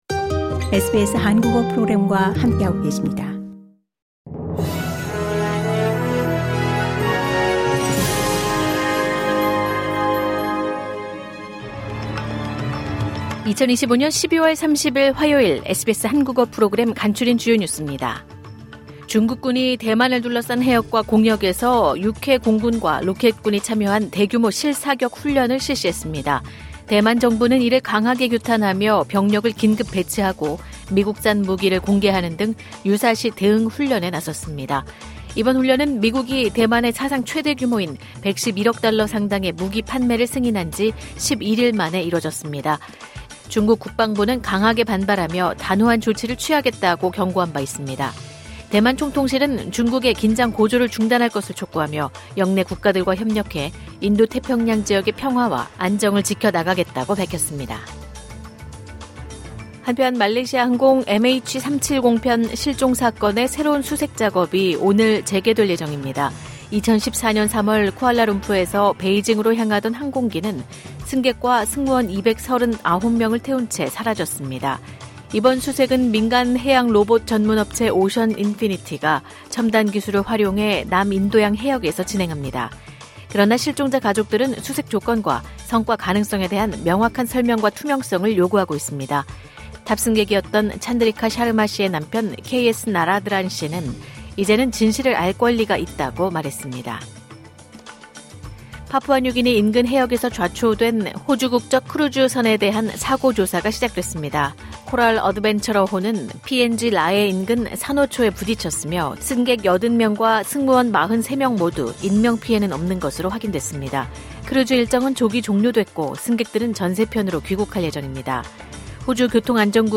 호주 뉴스 3분 브리핑: 2025년 12월 30일 화요일